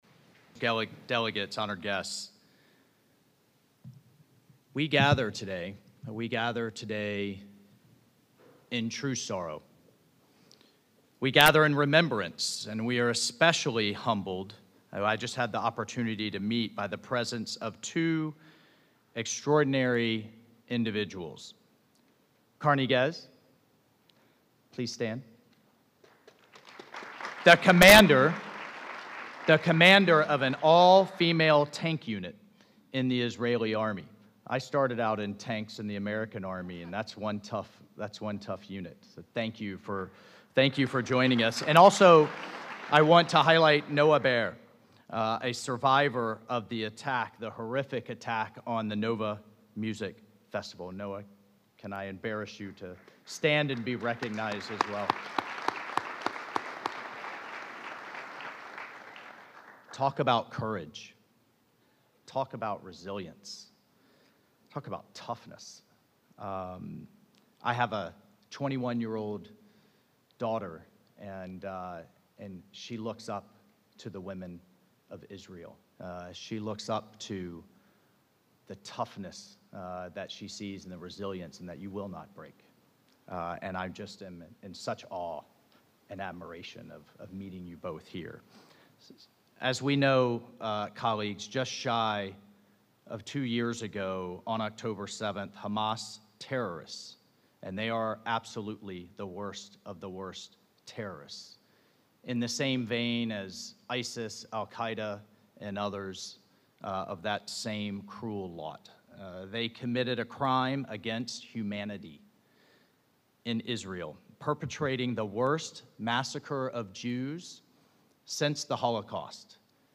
Address at a Commemoration Event Hosted by the Israeli Mission to the United Nations